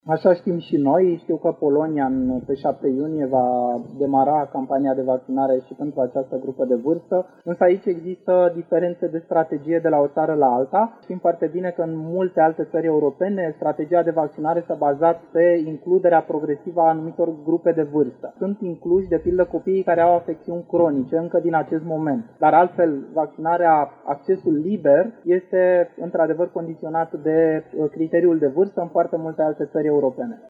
Coordonatorul campaniei naționale de vaccinare, doctorul militar Valeriu Gheorghiță: